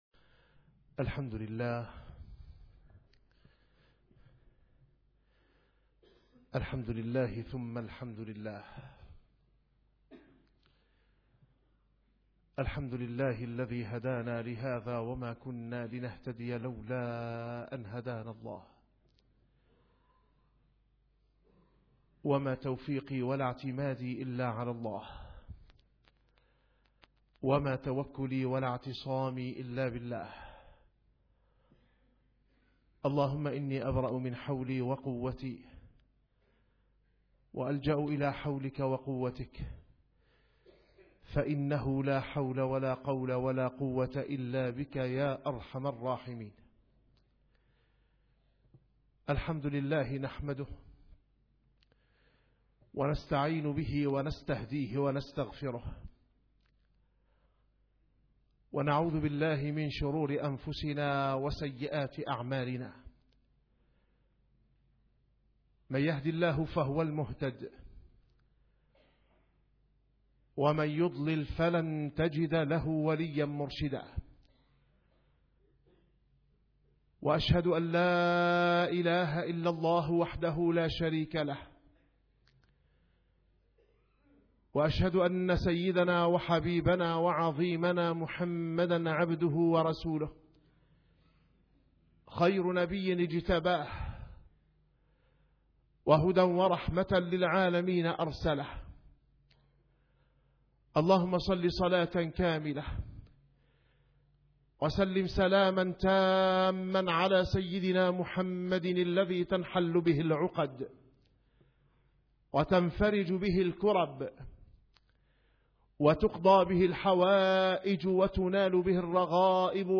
- الخطب - وفي الغار تستنبع الأنوار والأسرار